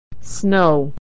Vowel Sound /ow/
ow-snow.mp3